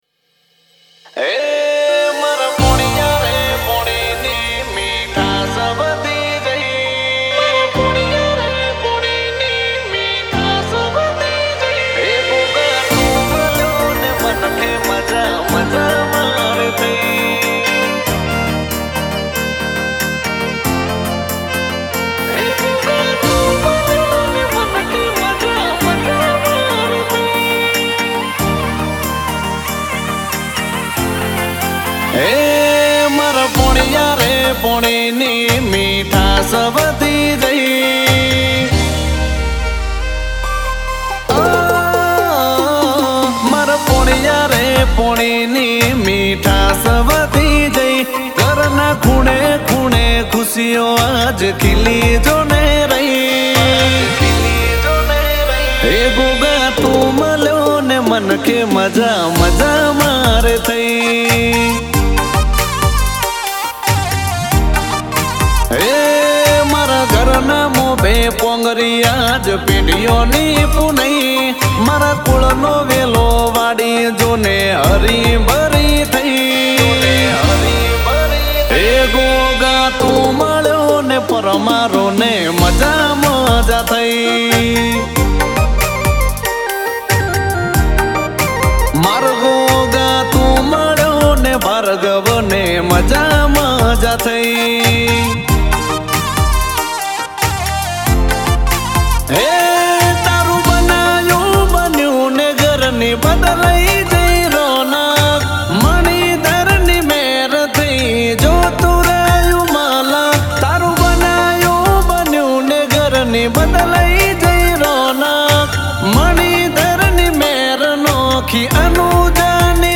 Category: Marathi Dj Remix Songs